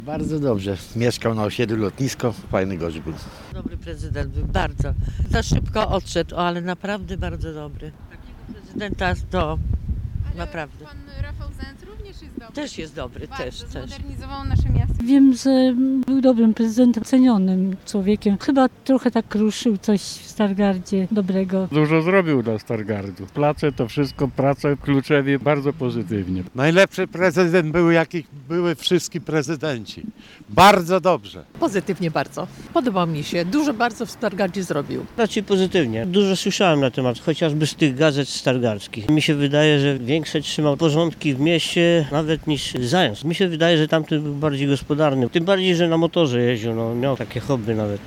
Zapytaliśmy ich, jak wspominają Prezydenta Pajora.